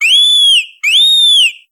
Fichier:Cri 0741 Pom-Pom SL.ogg
contributions)Televersement cris 7G.